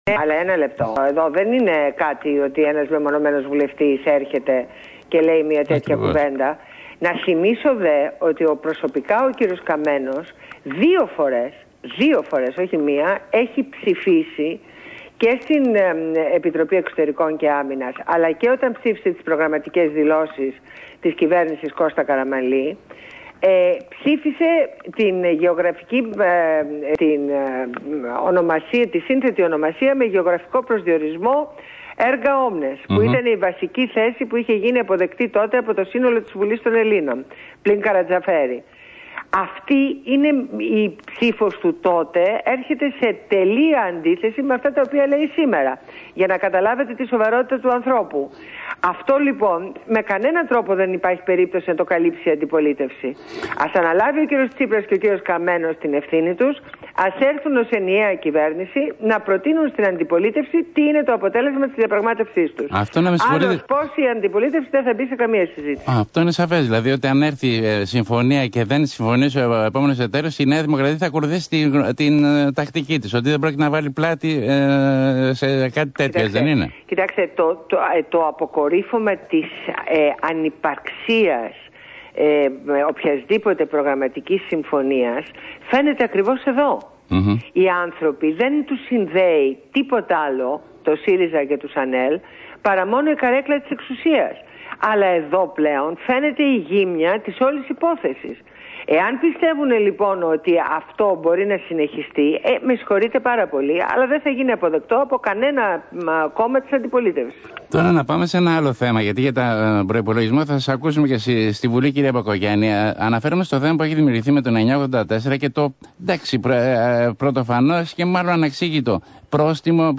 Συνέντευξη στο ραδιόφωνο Αθήνα 9,84.